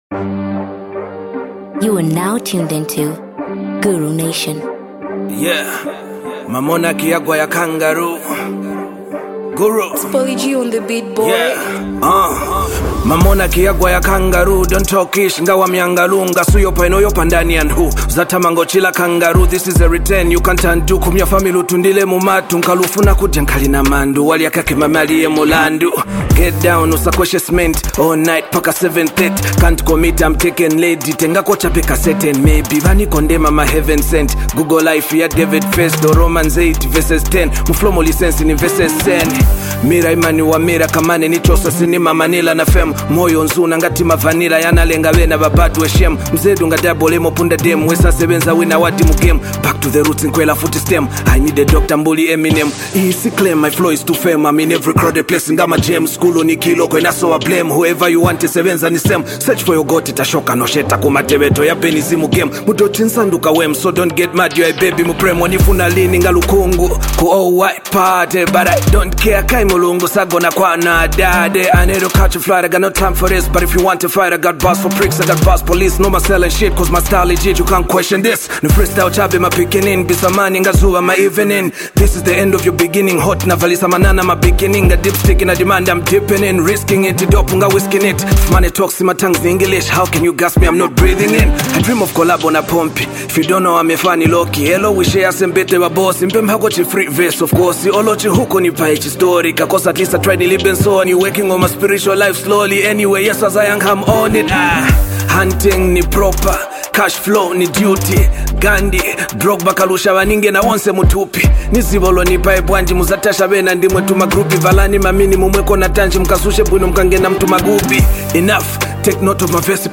raw and energetic freestyle
with clever wordplay and a hard-hitting beat.